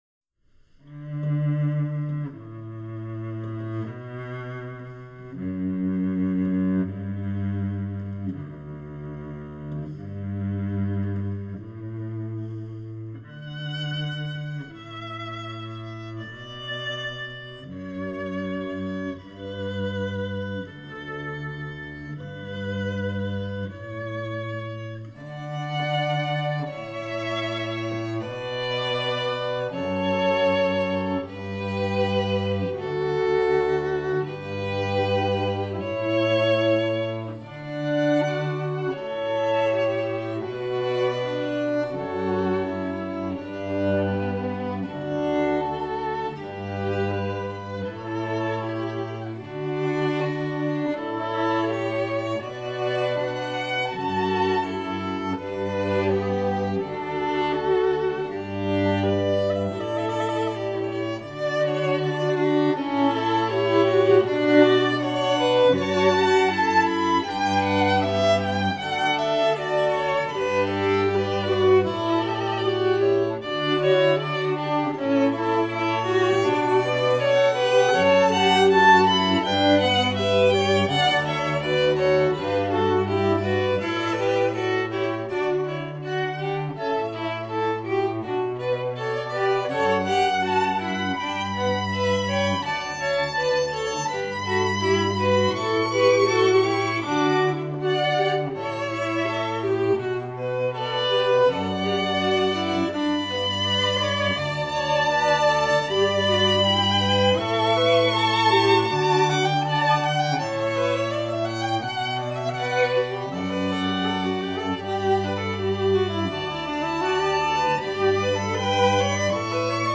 Ce départ doux qui monte en intensité et puissance au cours du morceau correspond bien aux émotions du jour de mariage.
• en quatuor ( deux violons, un alto et un violoncelle).
La version quatuor, la plus orchestrale